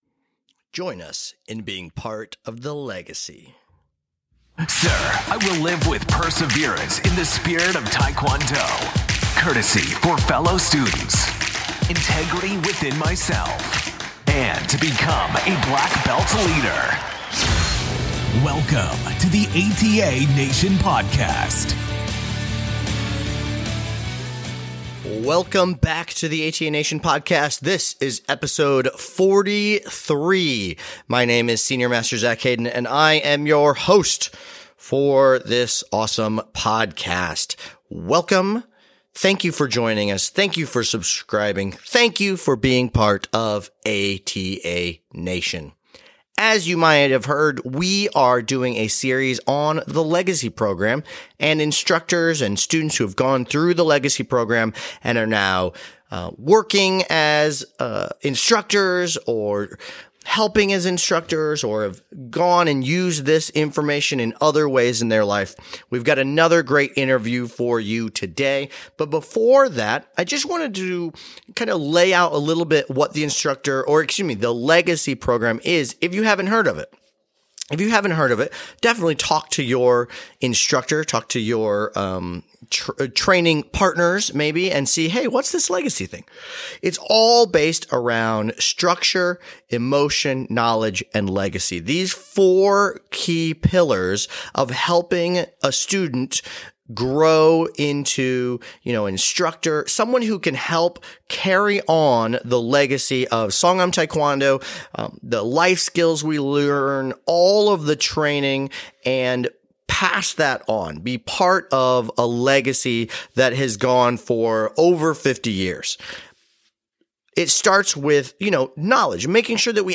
The Legacy program has something for everyone and this interview bring another aspect of the training to you.